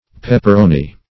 Pepperoni \Pep"per*o*ni\, n. a hard sausage of beef and pork, highly seasoned.